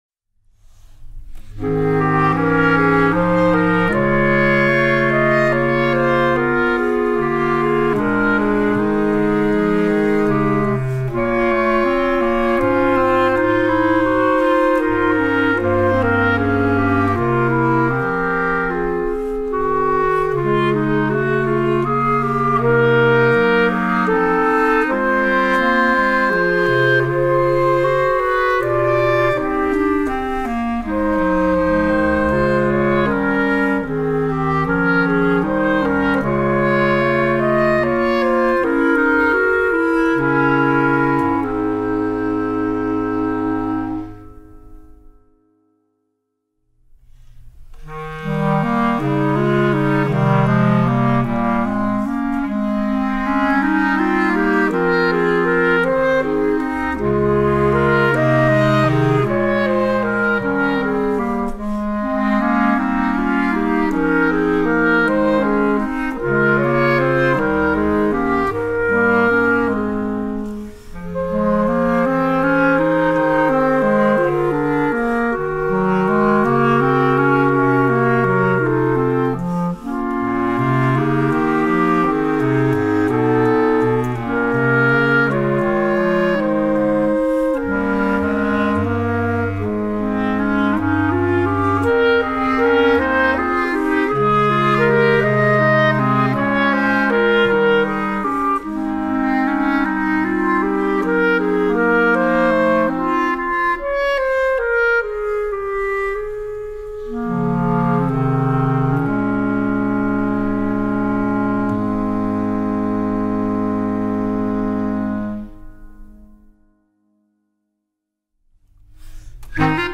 B♭ Clarinet 1 B♭ Clarinet 2 B♭ Clarinet 3 Bass Clarinet
单簧管四重奏
第一乐章…各乐器复杂交织的众赞歌风格曲目。和声随节拍变换，音乐伸缩反复，在营造期待感中结束乐章。
第三乐章…轻快动机在各乐器间纵横驰骋，在中段切分音为特色的节奏过后，乐曲悄然收束。